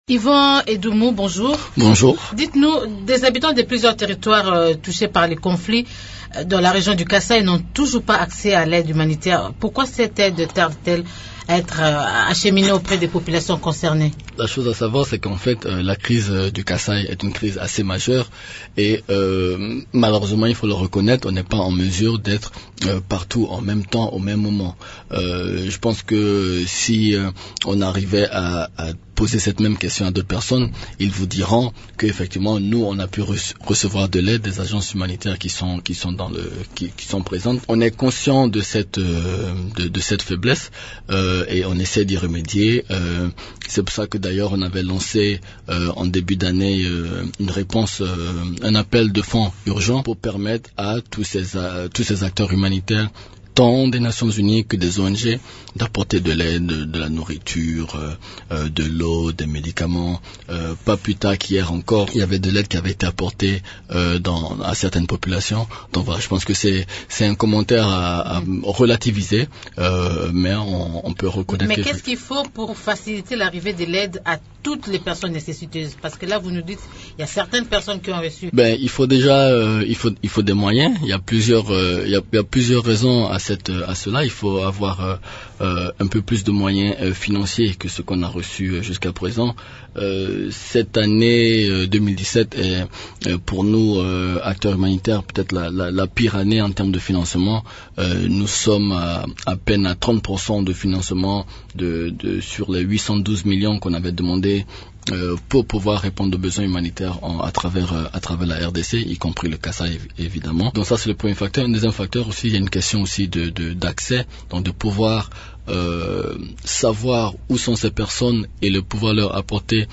Il est interrogé par